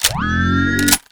combat / ENEMY / reload.wav
reload.wav